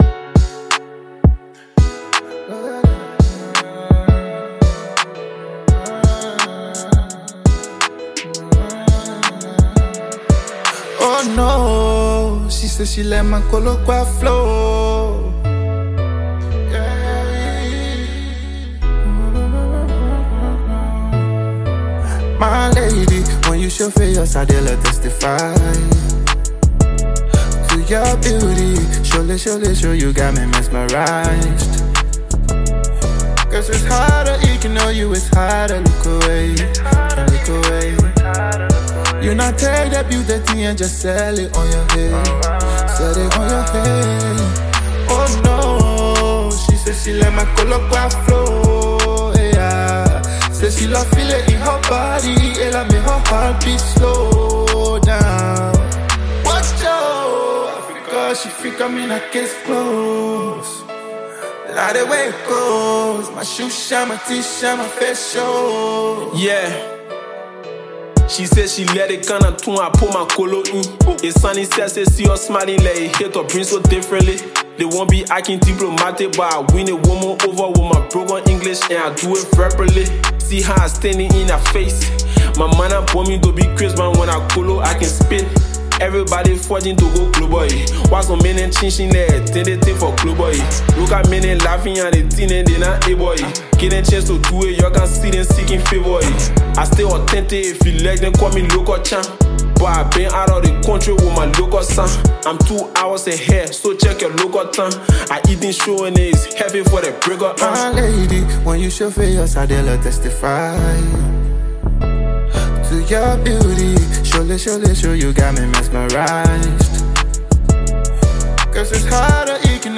/ Afro-Pop, Afrobeats, Hip-Co, Liberian Music / By